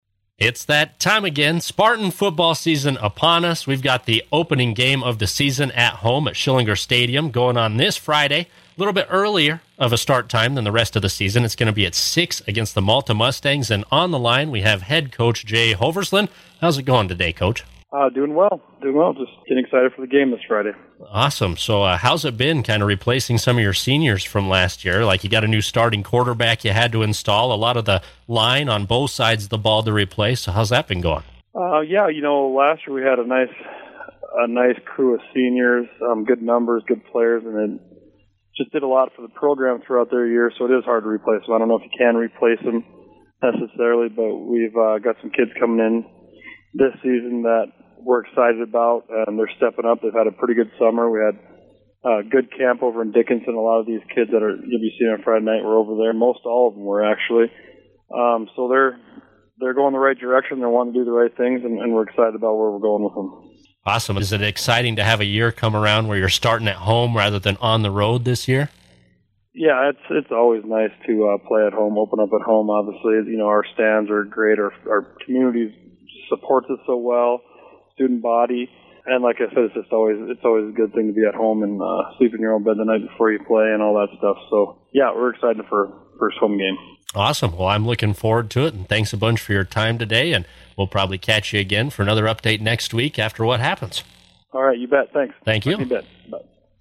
Here’s our conversation which aired during the pre-game of the 2024 Spartan football opener against the Malta Mustangs!